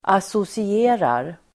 Uttal: [asosi'e:rar]
associerar.mp3